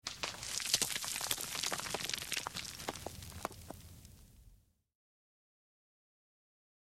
На этой странице собраны звуки камнепада — от легкого шелеста скатывающихся камешков до грохота крупных обвалов.
Звук земли и мелких камней, сыплющихся на голову сверху